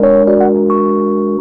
08 Rhodes 28.wav